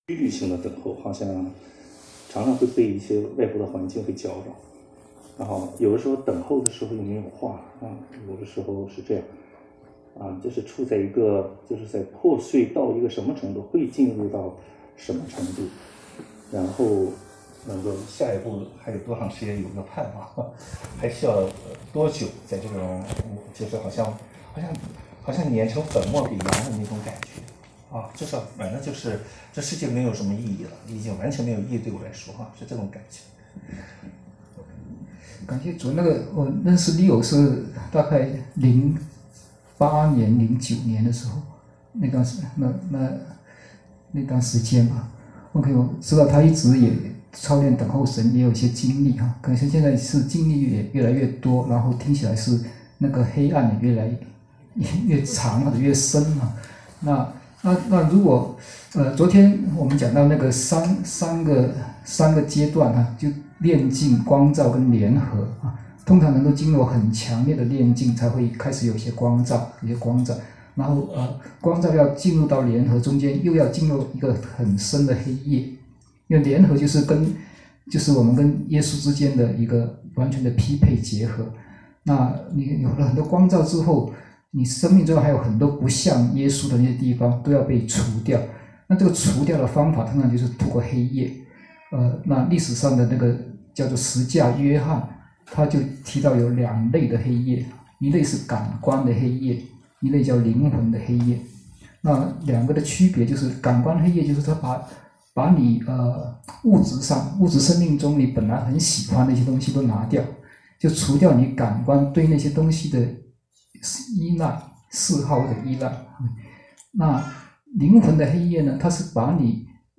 座谈会